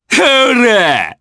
Ezekiel-Vox_Attack3_jp.wav